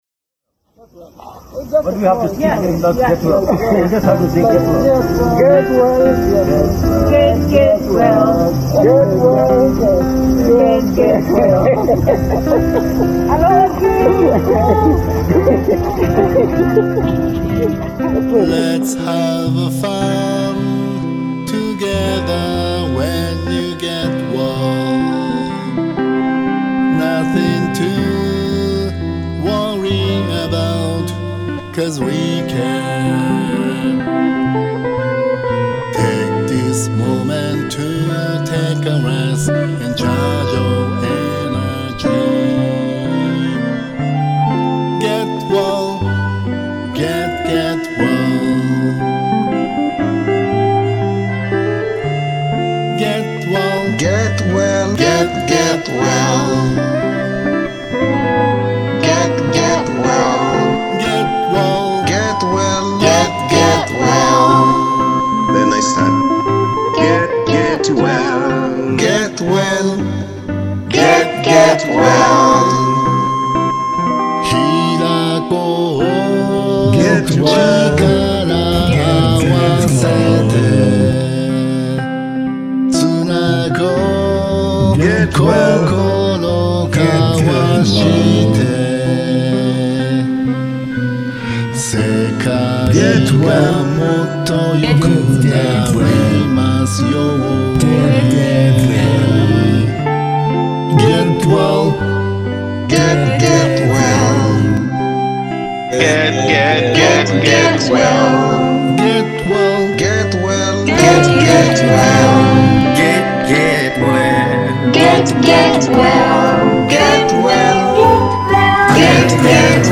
カワイイコーラス追加